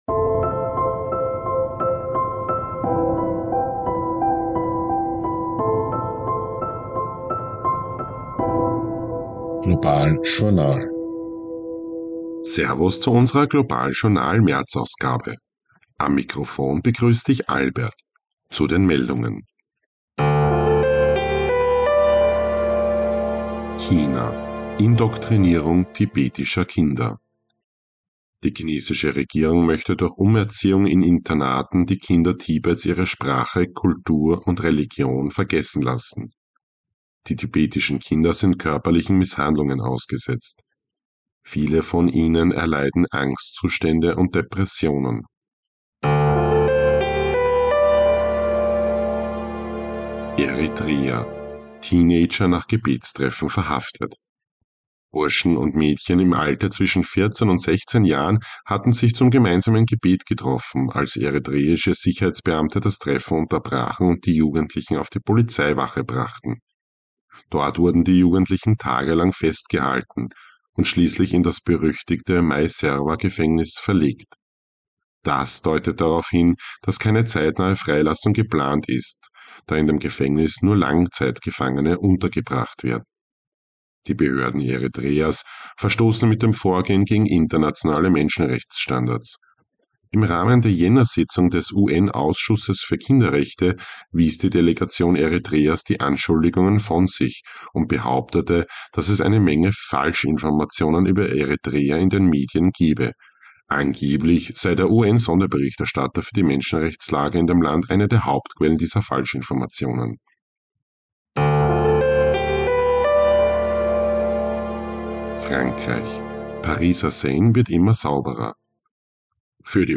News Update März 2025